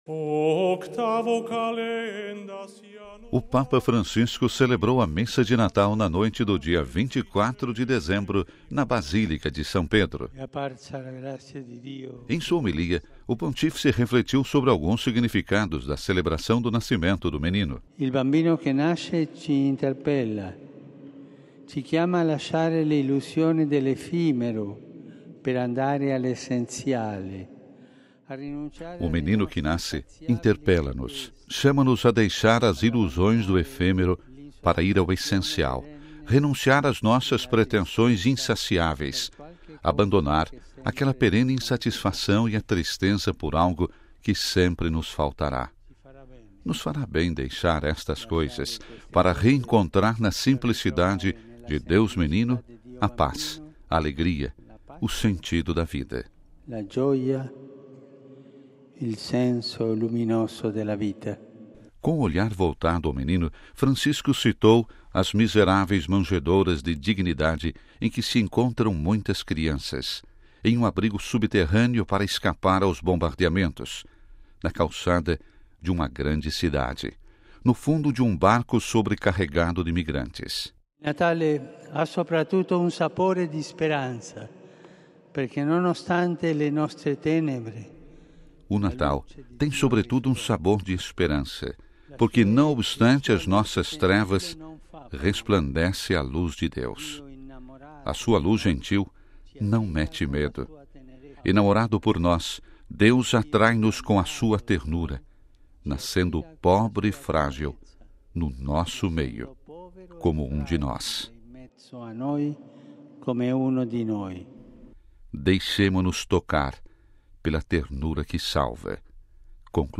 Rádio Vaticano (RV) – O Papa Francisco celebrou a Missa de Natal na noite do dia 24 de dezembro na Basílica de São Pedro.